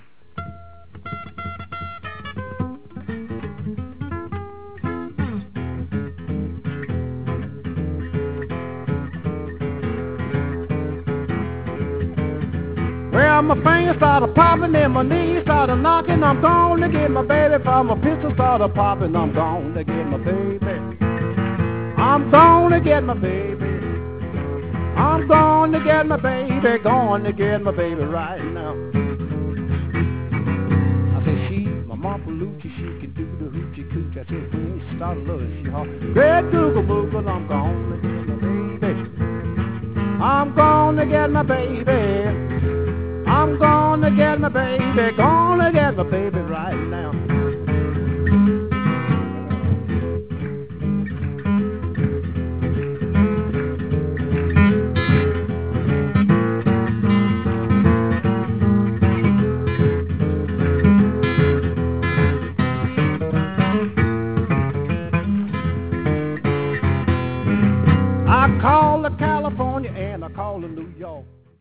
Piedmont Blues
Piedmont blues of the Carolinas and Georgia were more influenced by ragtime, white country music, and popular song than their Delta counterpart.